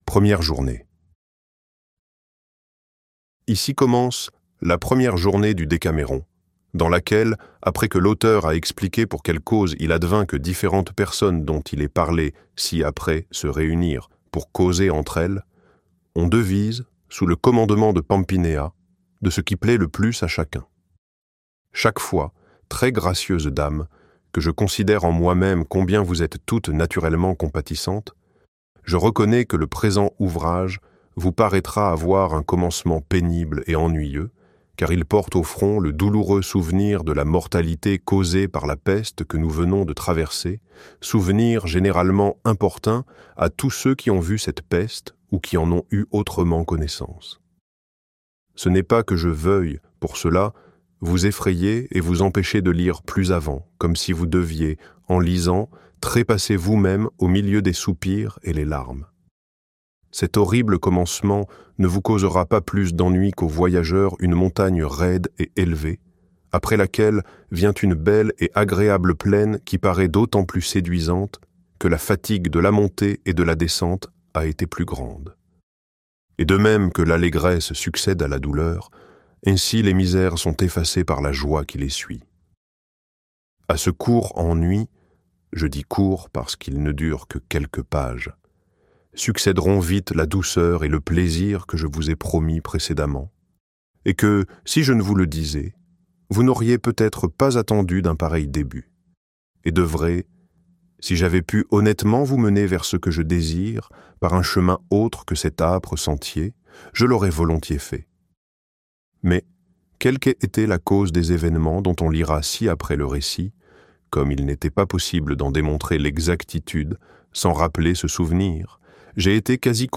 Le Décaméron - Livre Audio
Extrait gratuit